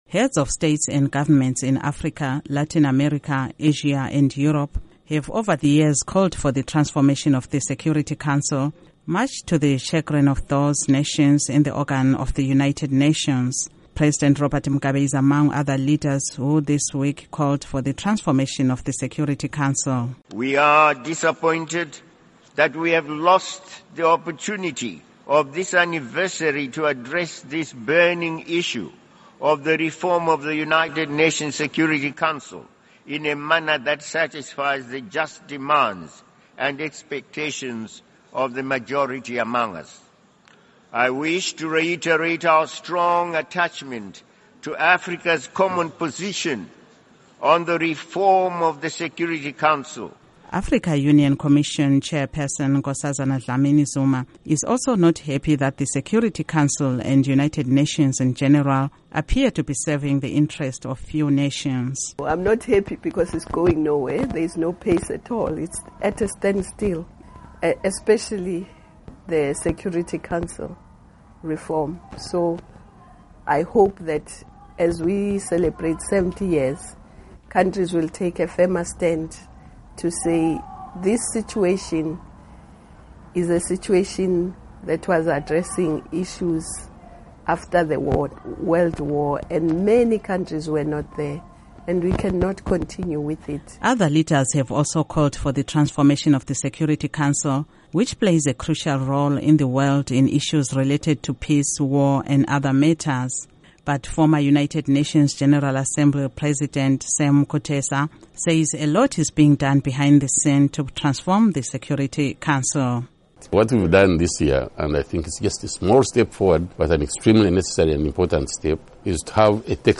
Report on UN Security Council Transformation